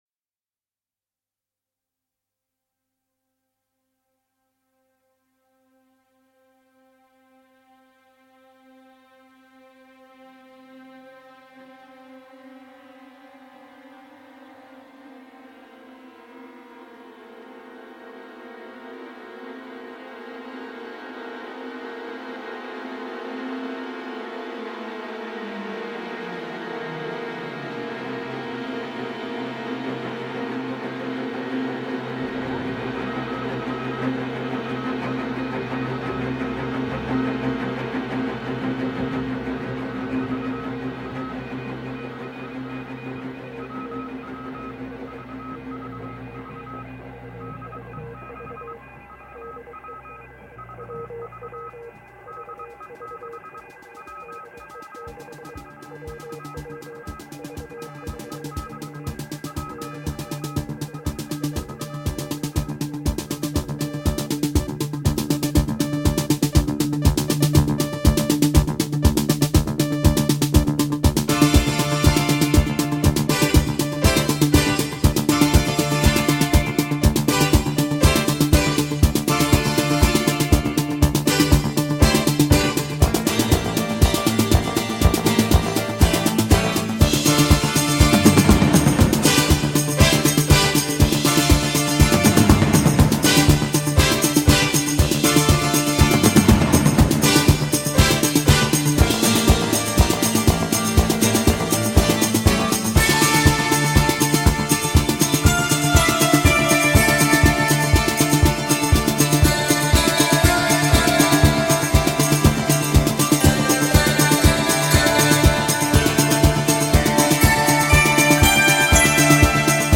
Progressive Rock / Art Rock